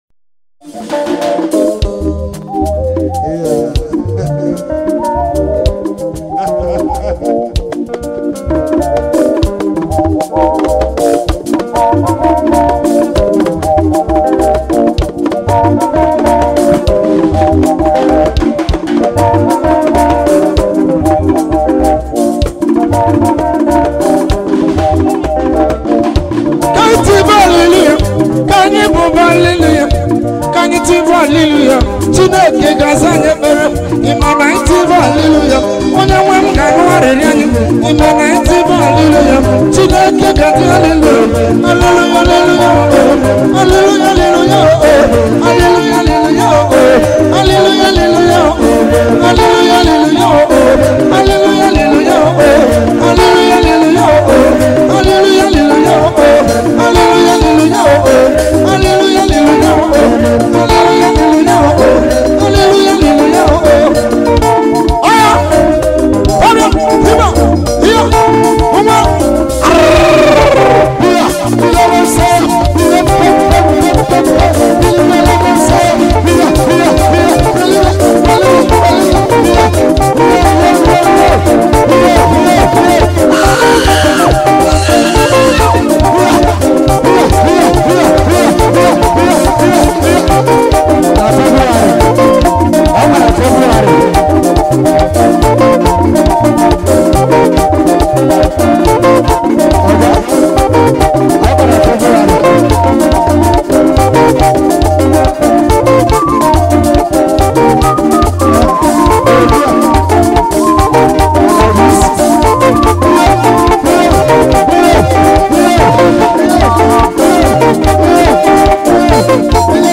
Nigerian contemporary Igbo Gospel music
September 9, 2024 admin Gospel, Music 0